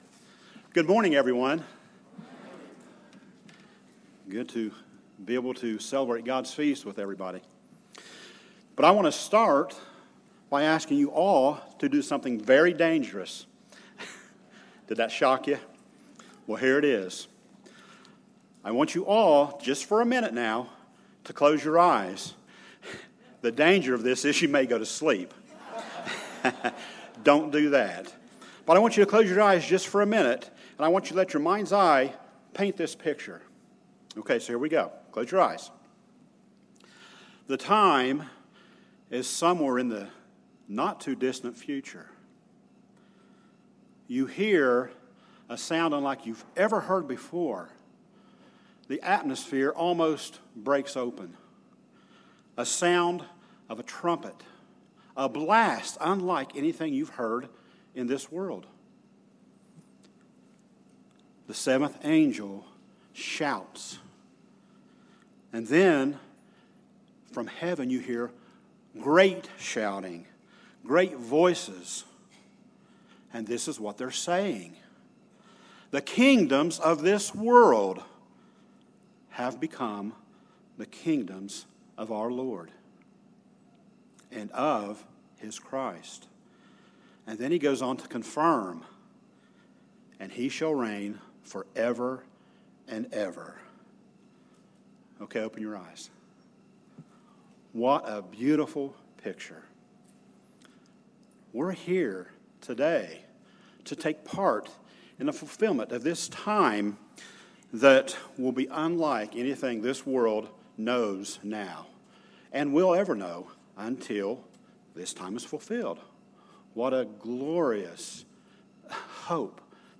This sermon was given at the White Haven, Pennsylvania 2017 Feast site.